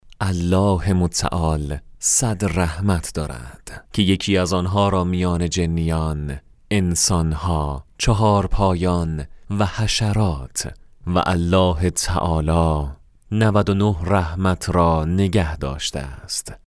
Male
Young
Adult
Now, he has his own home studio and can energetically read and record any type of script you can imagine.
Holy-Quran